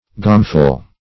Gameful \Game"ful\ (g[=a]m"f[.u]l), a. Full of game or games.
gameful.mp3